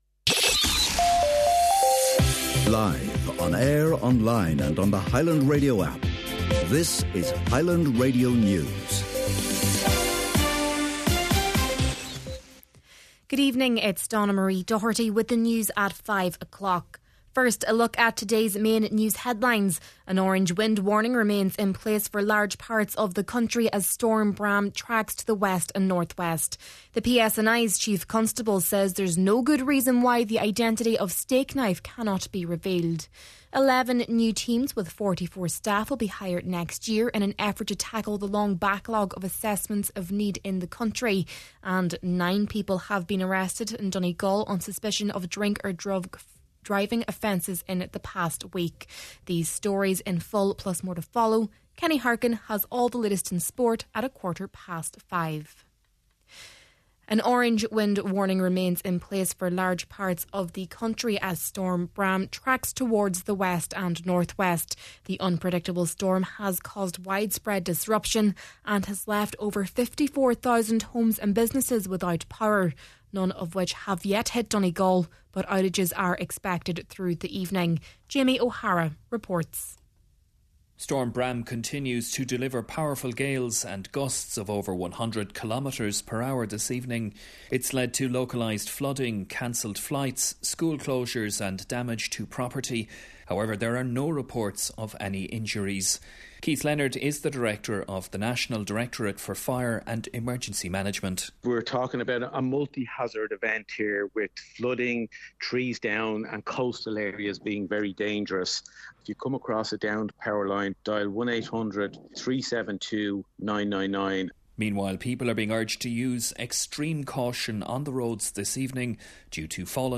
Main Evening News, Sport and Obituary Notices – Tuesday, December 9th